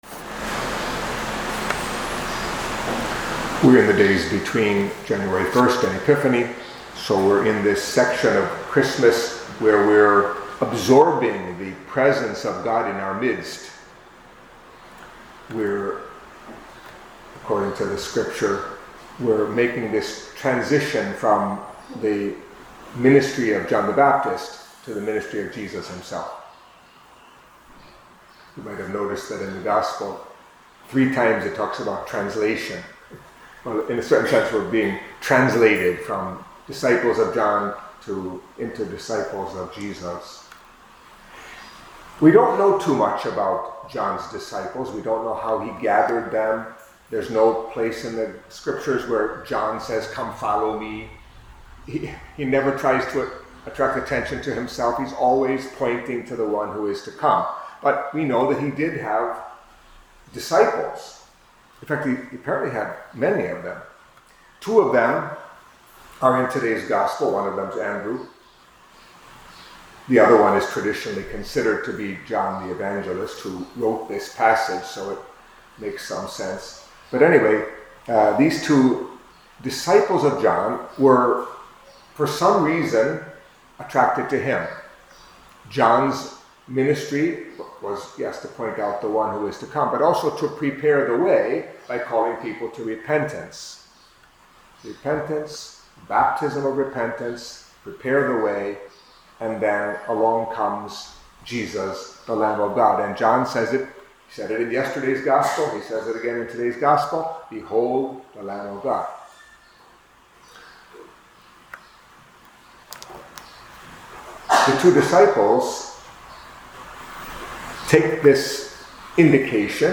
Catholic Mass homily for Thursday